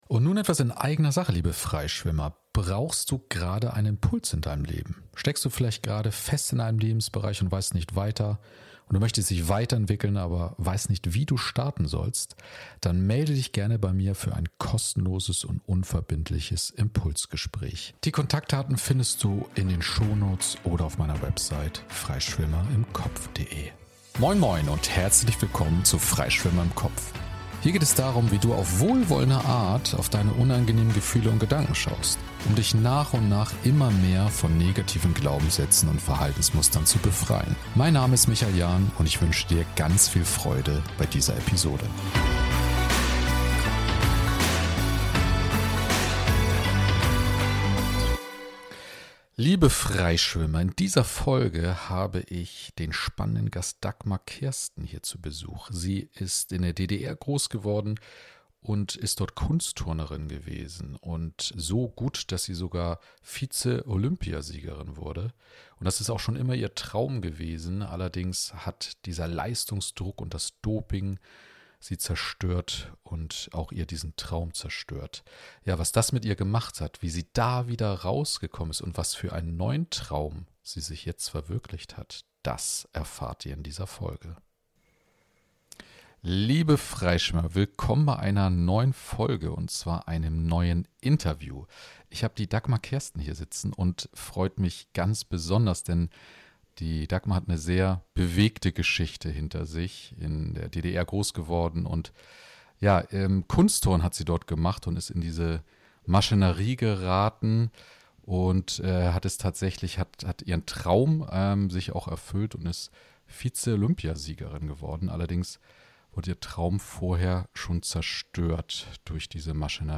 034: Raus aus der Opferrolle in ein glückliches Leben - im Gespräch mit Dopingopfer und Vize-Olympiasiegerin Dagmar Kersten ~ Freischwimmer im Kopf - Befreie Dein wahres Selbst Podcast